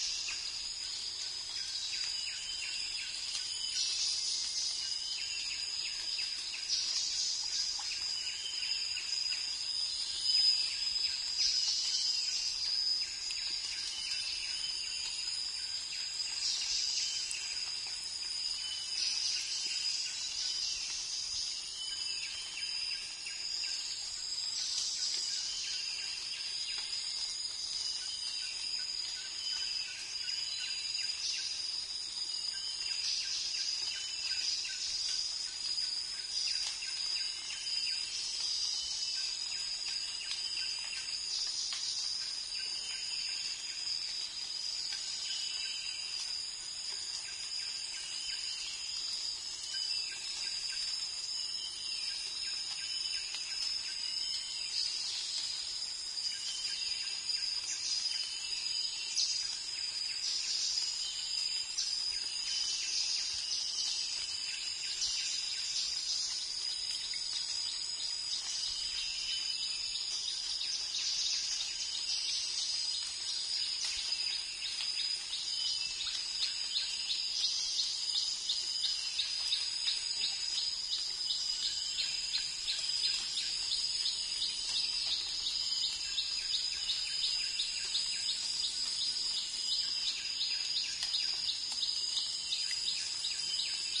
泰国 " 泰国丛林清晨的蟋蟀、鸟儿的回声+水滴在植物上的声音3已清洗干净
描述：泰国丛林早晨的蟋蟀，鸟儿的回声+植物上的水滴被清洗干净
标签： 鸟类 泰国 丛林 蟋蟀 早晨 现场录音
声道立体声